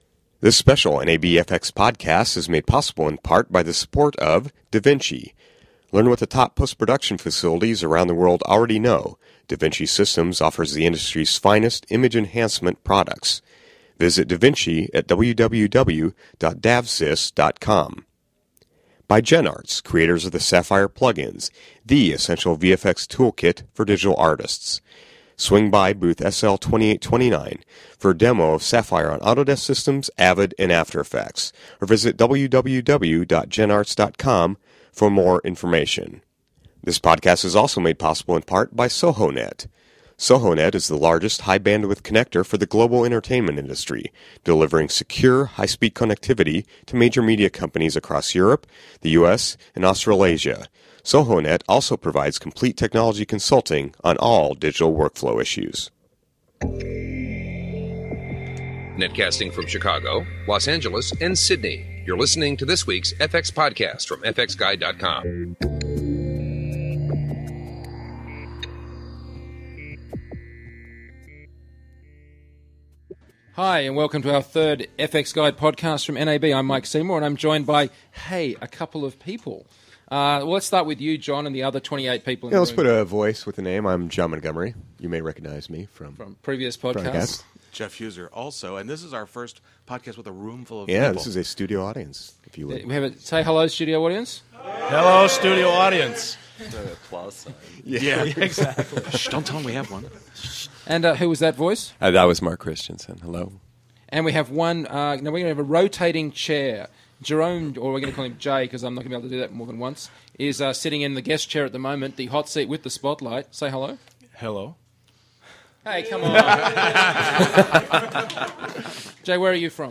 For today's podcast we invited various guests as well a fxphd professors and postgrads to join us in a sort of bar camp where everyone reports back on what they saw on the show floor and discusses.